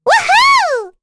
Rehartna-Vox_Happy7.wav